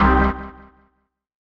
ORGAN-04.wav